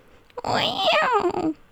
CATERPIE.wav